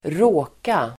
Uttal: [²r'å:ka]